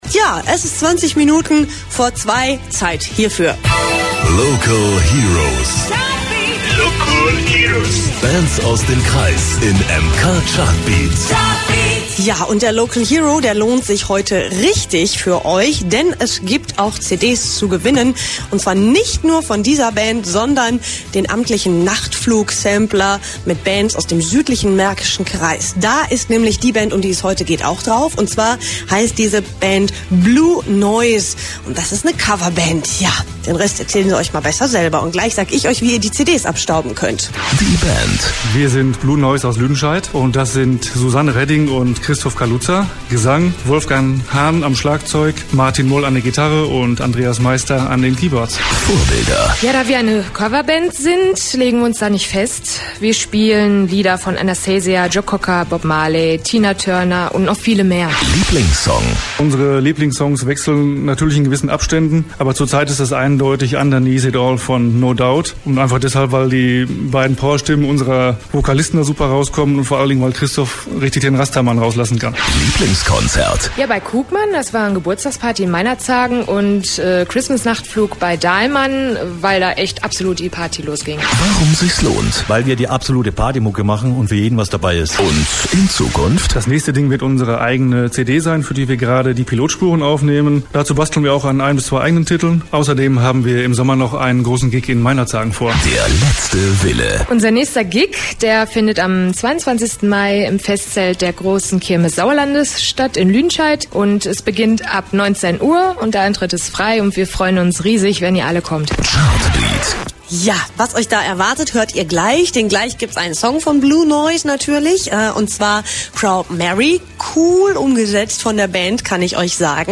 Interview mit Blue Noize bei Radio MK - Chartbeat